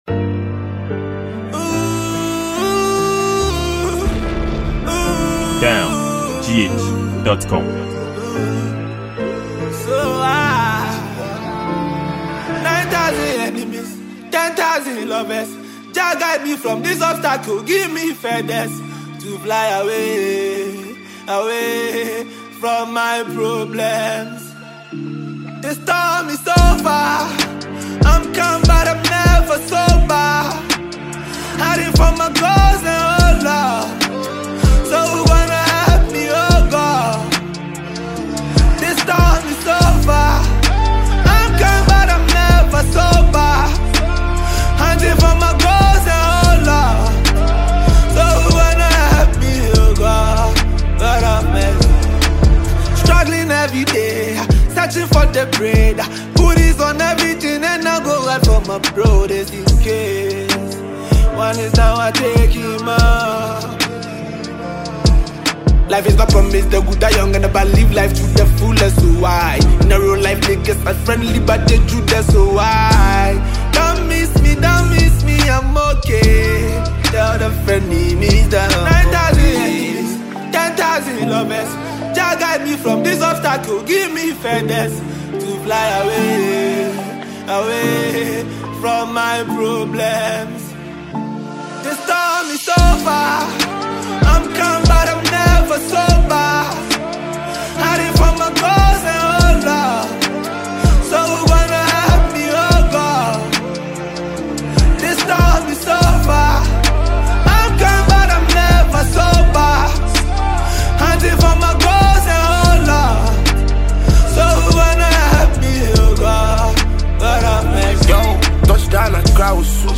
a Free Mp3 featuring talented Nigerian rapper